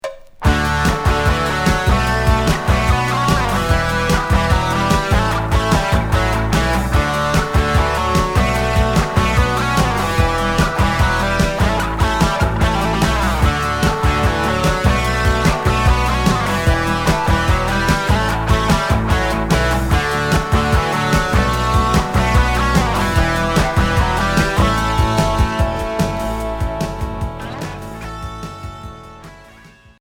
Rock Cinquième 45t